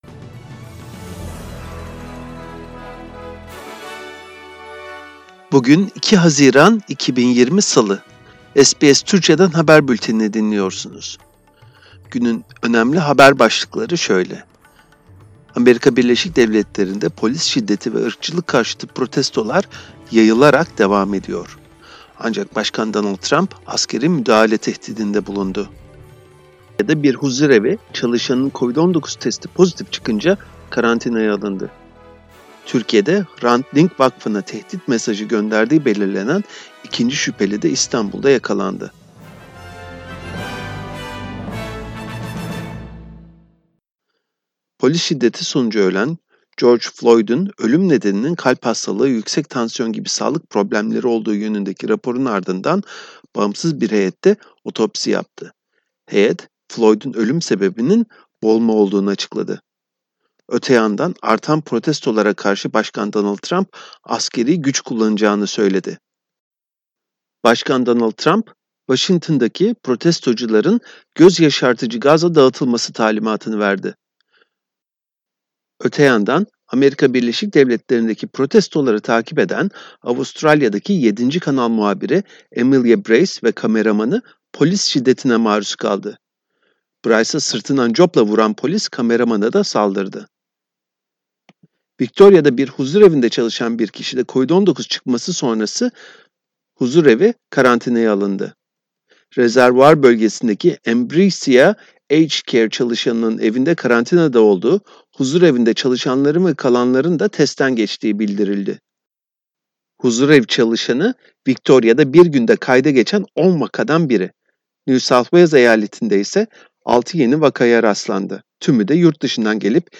SBS Türkçe Haberler 2 Haziran